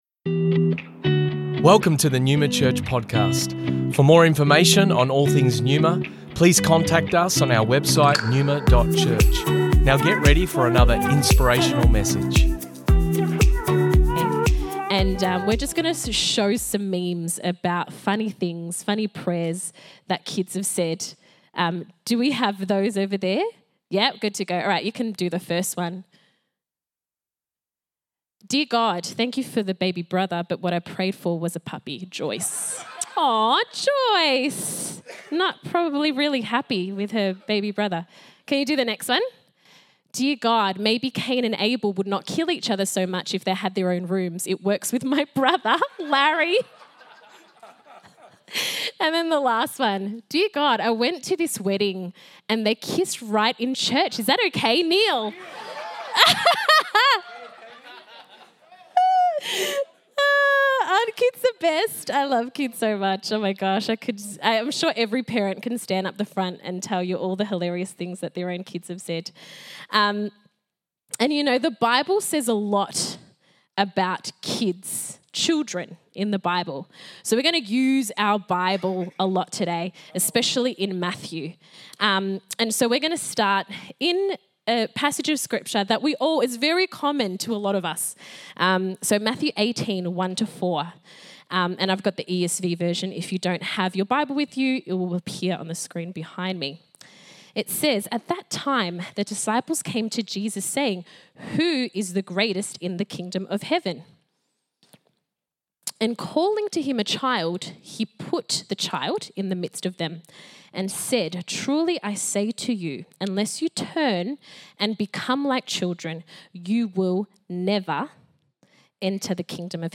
Originally recorded at Neuma Melbourne West Dec 3rd 2023